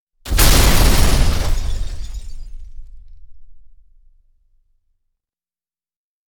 Explosion (RUN).wav